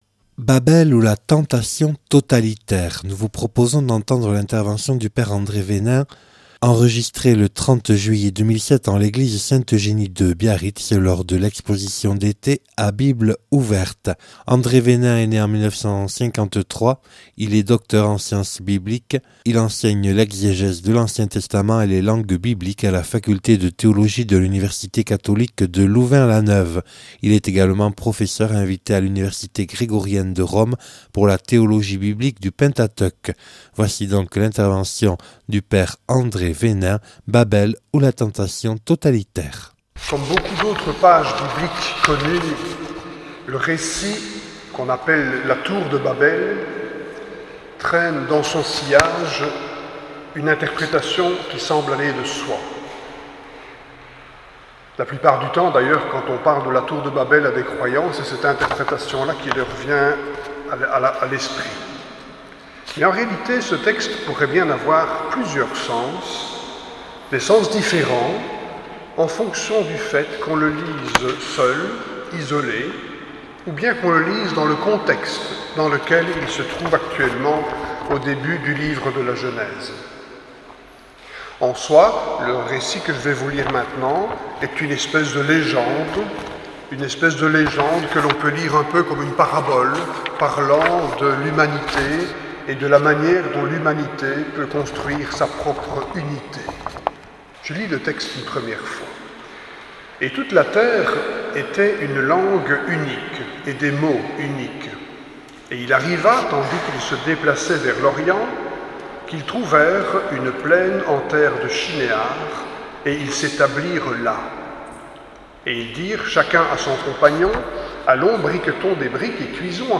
Enregistrée le 30/07/2017 en l’église sainte Eugénie de Biarritz.